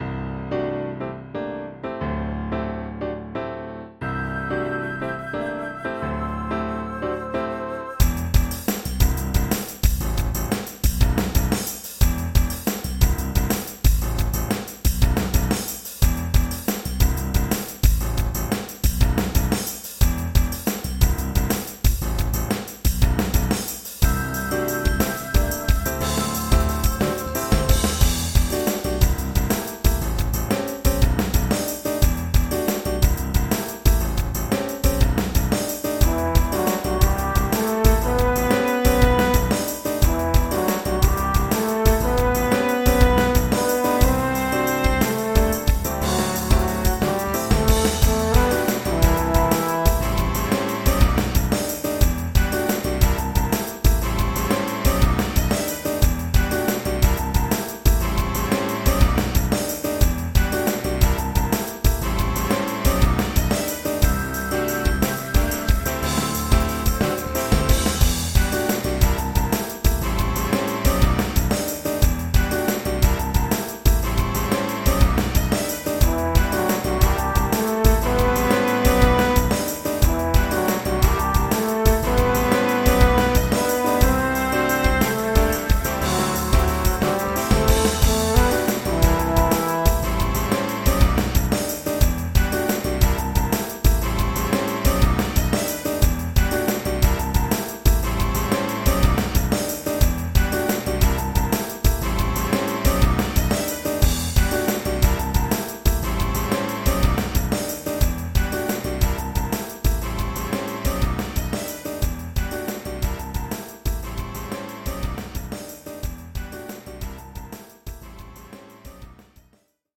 traccia di accompagnamento